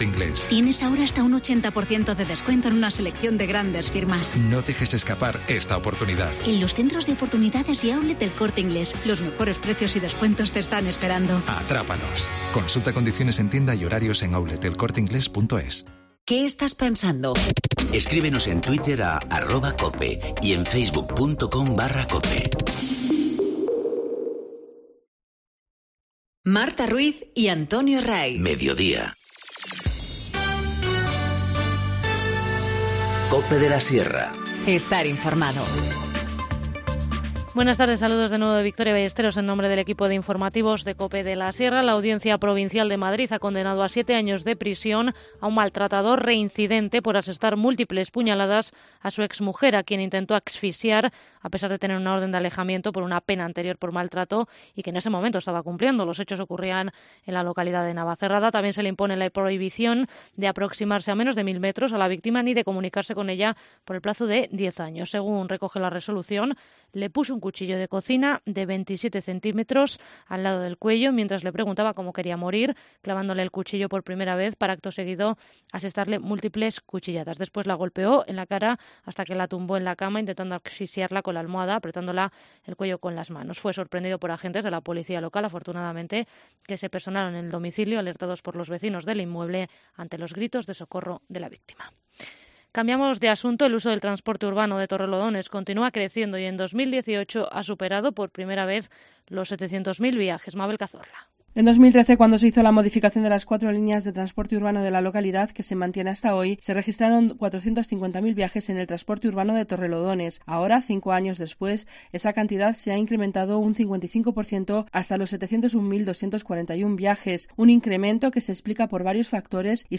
Informativo Mediodía 11 enero- 14:50h